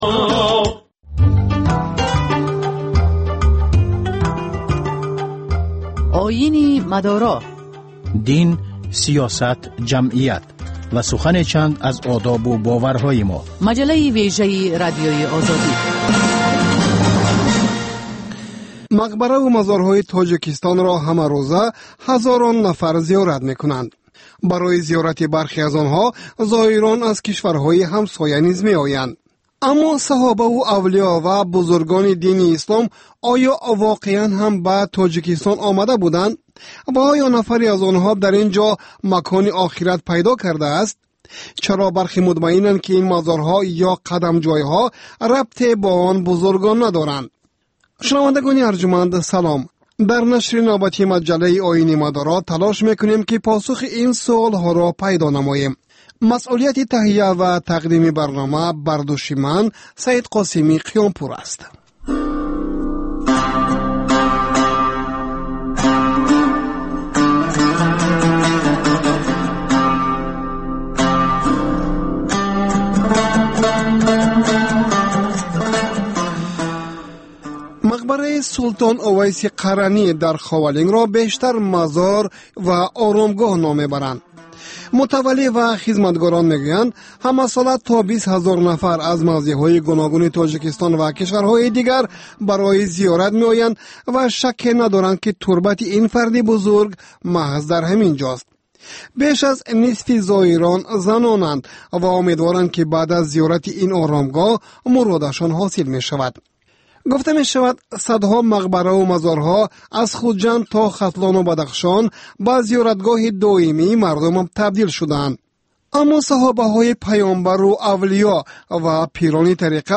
Дин ва ҷомеа. Гузориш, мусоҳиба, сӯҳбатҳои мизи гирд дар бораи муносибати давлат ва дин.